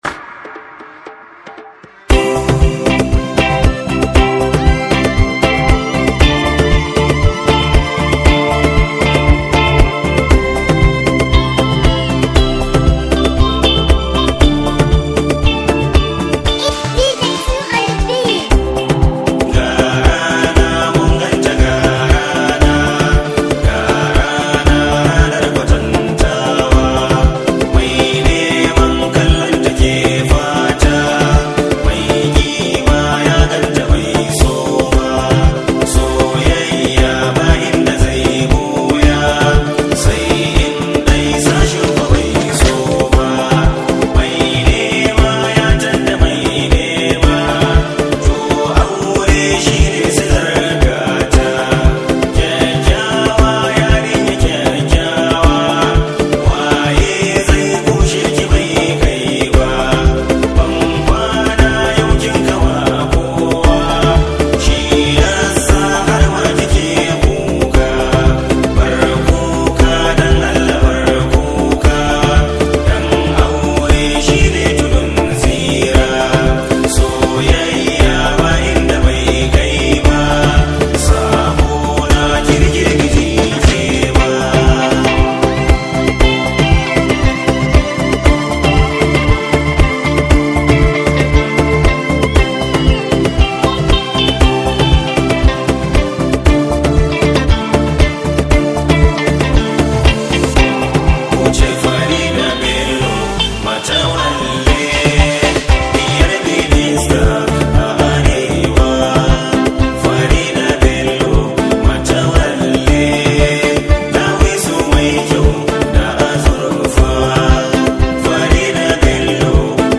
Hausa Songs
sabuwar waƙar biki mai ratsa zuciya